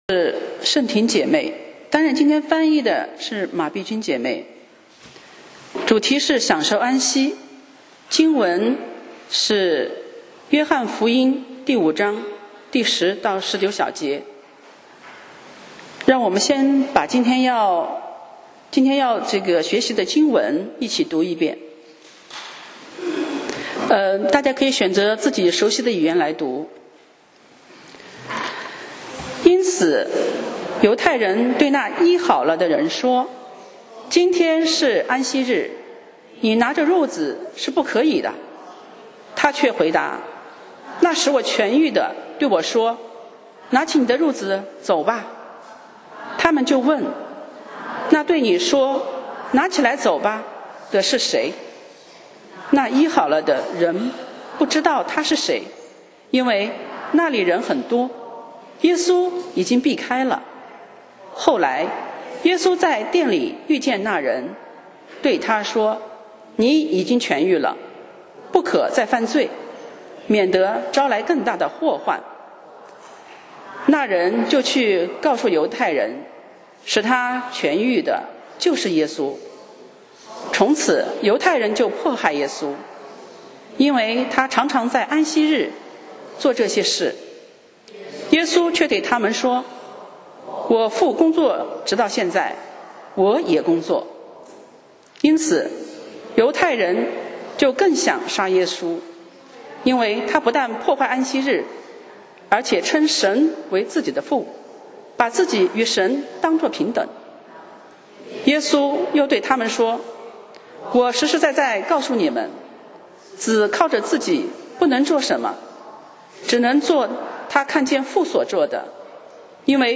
講道 Sermon 題目 Topic：享受安息 經文 Verses：约翰福音5:10-19. 10那天是安息日，所以犹太人对那医好的人说，今天是安息日，你拿褥子是不可的。